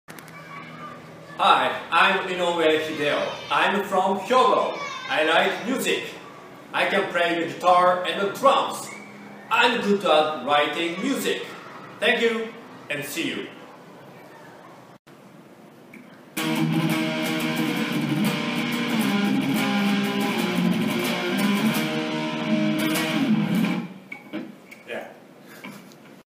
京都市立向島秀蓮小中学校 | 学校記事 | ６年生 英語で自己紹介「This is me.」 〜Version3〜